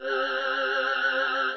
Pad (THE BROWN STAINS OF DARKEESE LATIFAH).wav